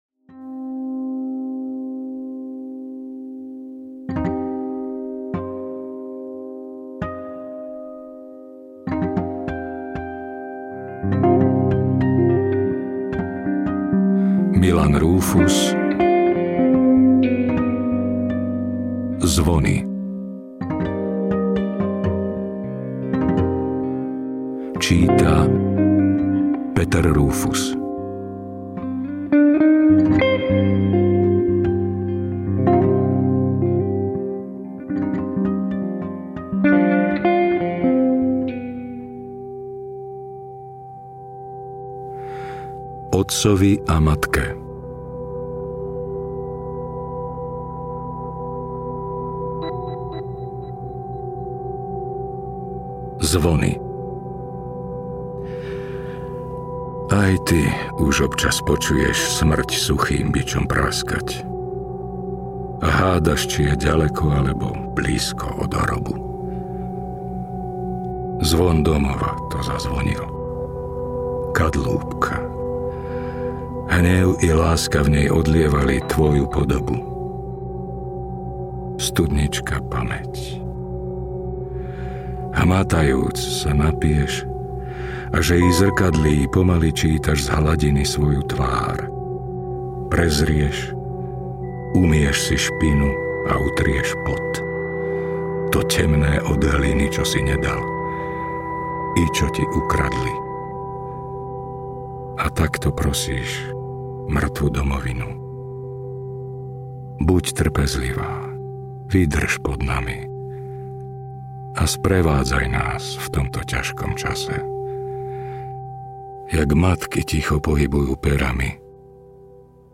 Zvony audiokniha
Ukázka z knihy